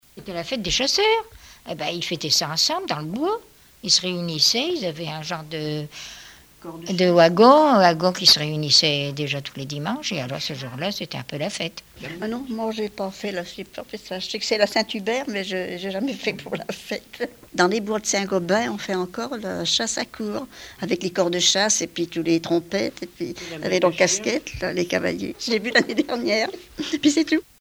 Témoignages liés aux rituels du calendrier
Catégorie Témoignage